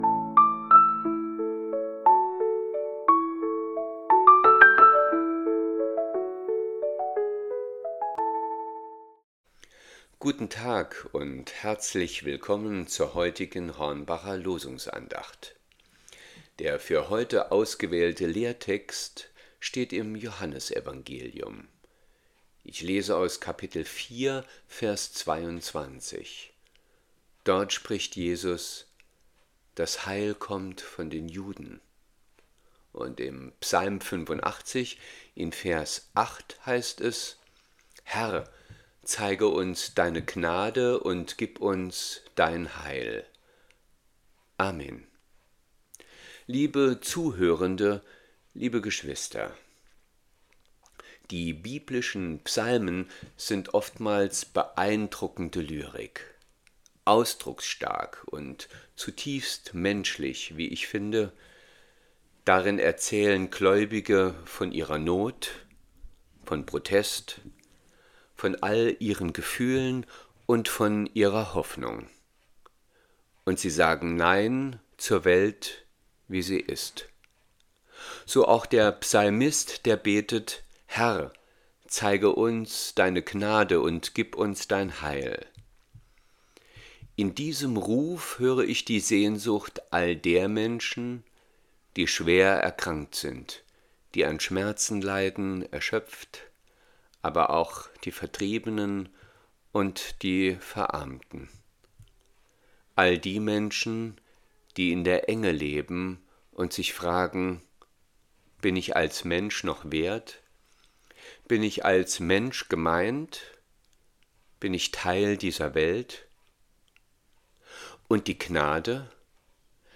Losungsandacht für Freitag, 05.12.2025 – Prot. Kirchengemeinde Hornbachtal mit der prot. Kirchengemeinde Rimschweiler